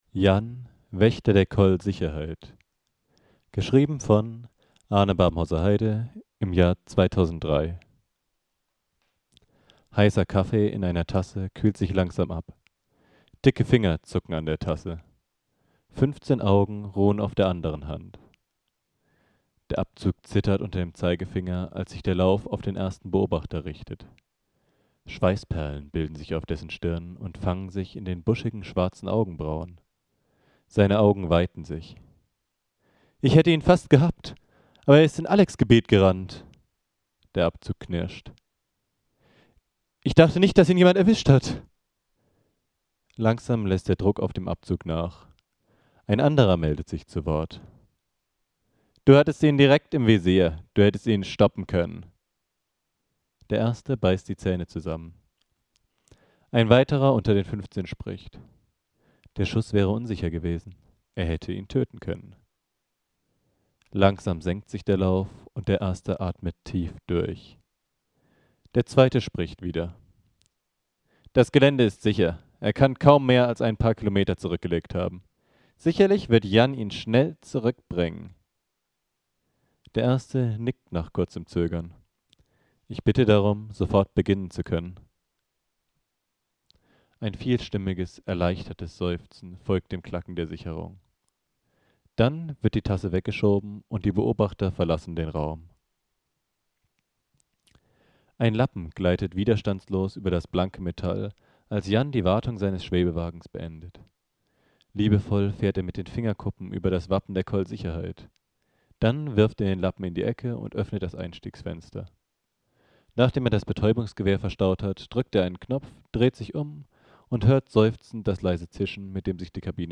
-(Phantastik) Jan, Wächter der Koll Sicherheit - Ein Gefangener ist entkommen. [>audiobook<] -(Phantastik) Was sollte er da bloss finden - Jahid träumt von einer Reise zu den Sternen.